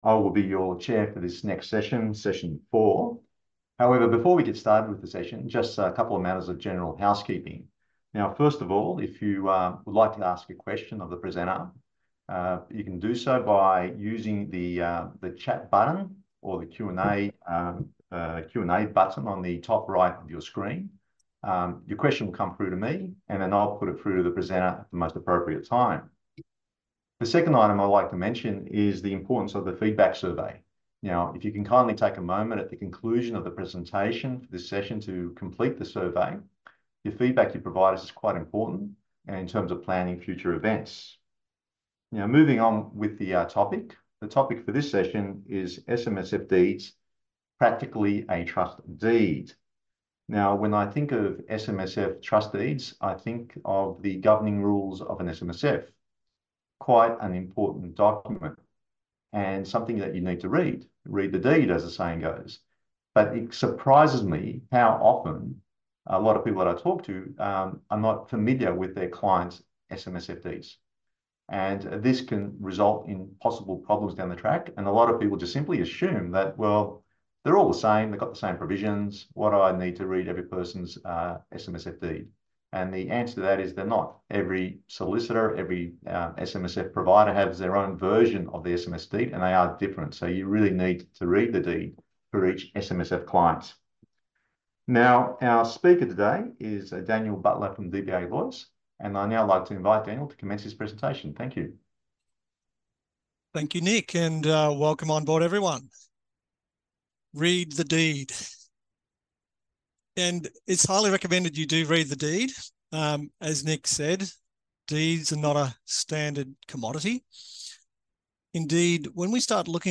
Took place at: Online